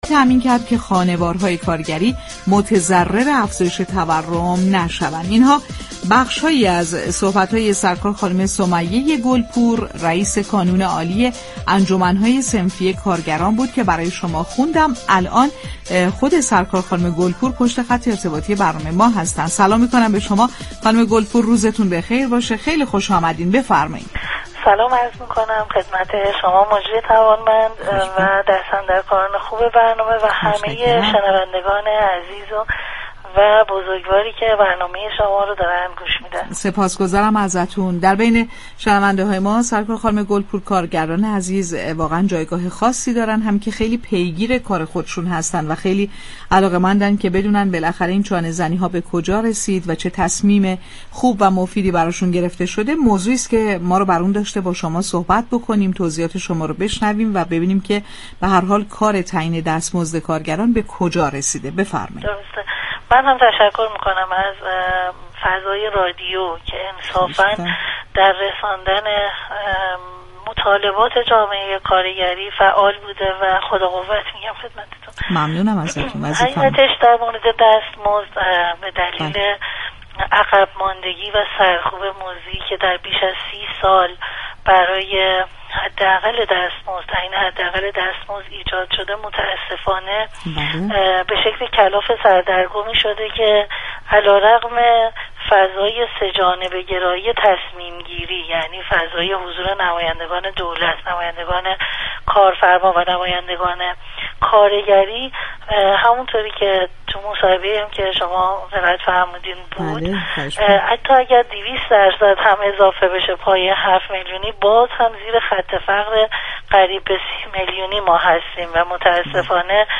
در گفت و گو با بازار تهران